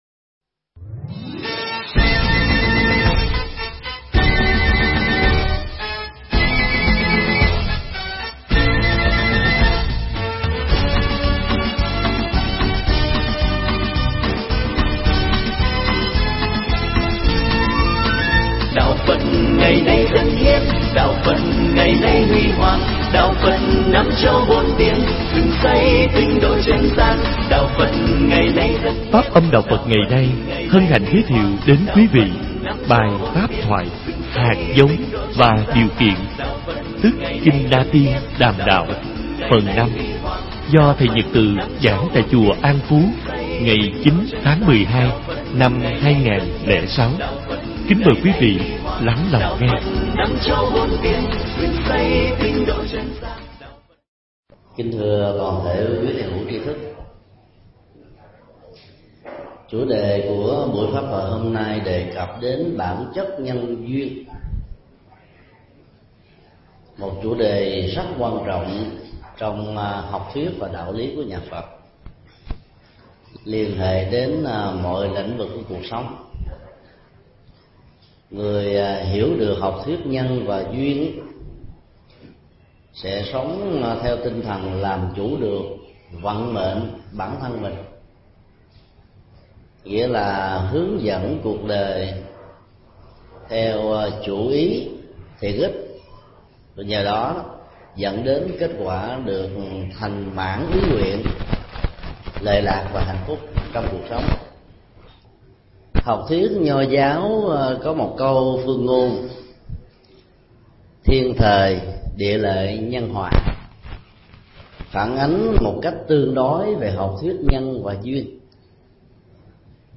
Mp3 Thuyết pháp Hạt Giống Và Điều Kiện – Phần 1/2 – thầy Thích Nhật Từ giảng tại Chùa An Phú, ngày 09 tháng 12 năm 2006.